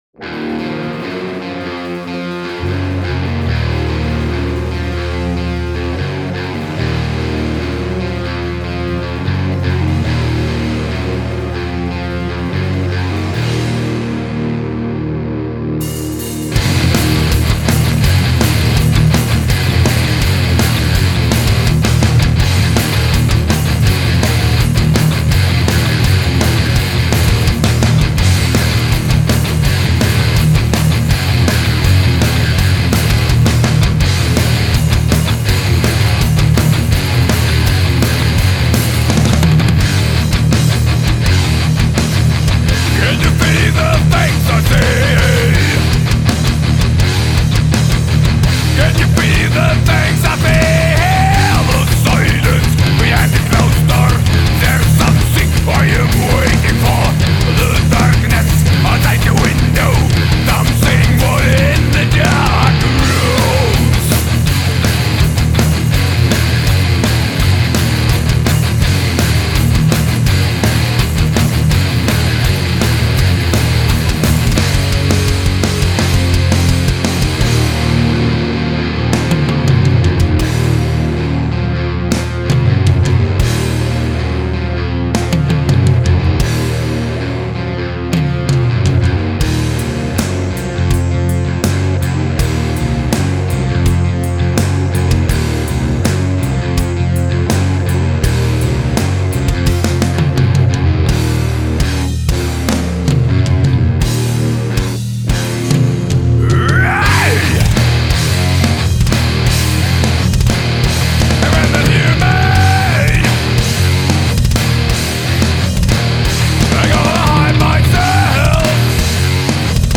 vocals, guitar
bass
drums